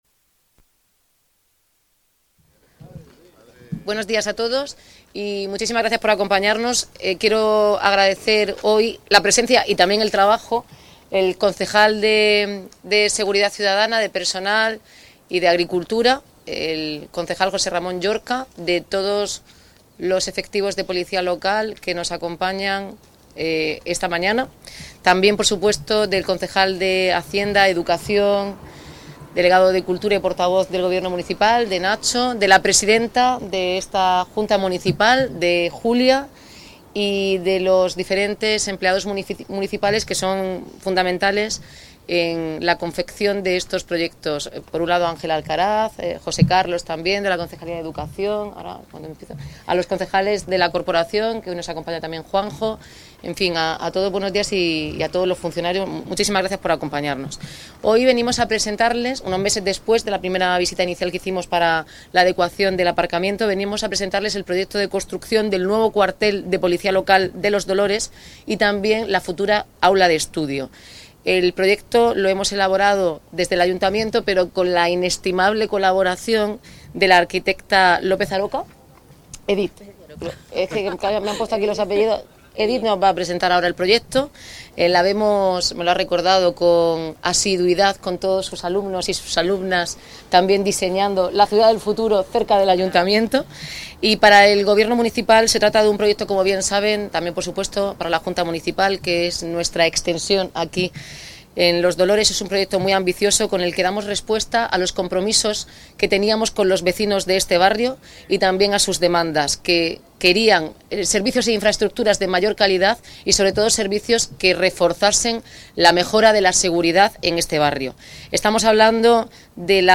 Declaraciones de la alcaldesa